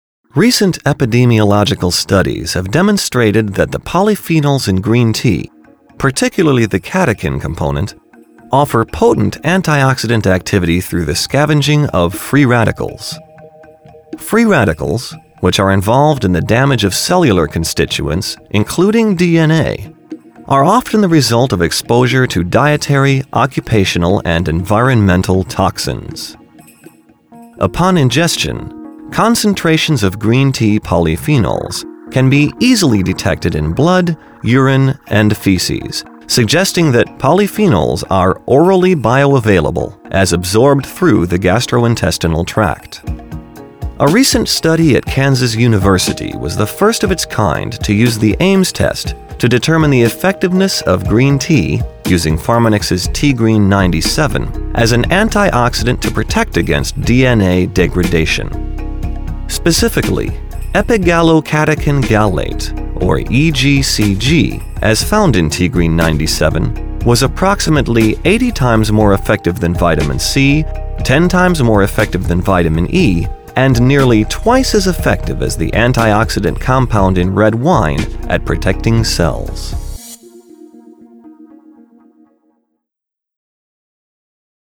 Corporate Showreel
Male
American Standard
Bright
Confident
Upbeat